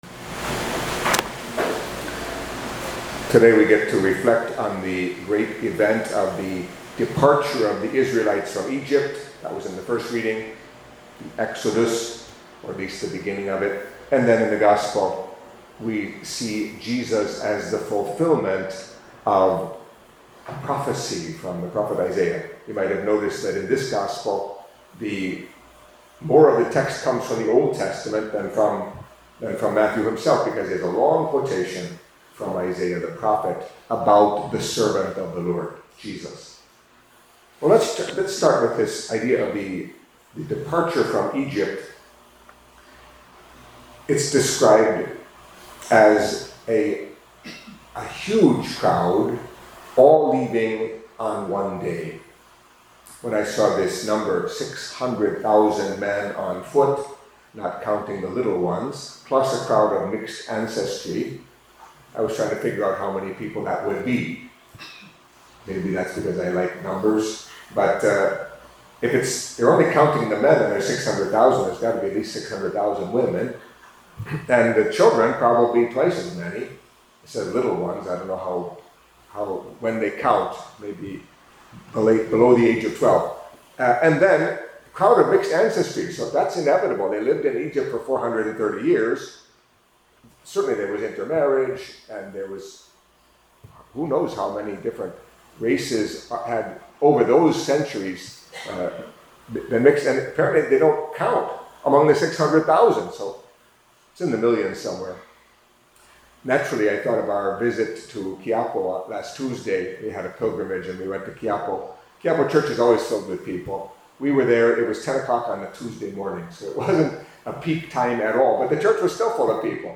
Catholic Mass homily for Saturday of the Fifteenth Week in Ordinary Time